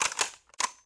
Minigun_Reload.ogg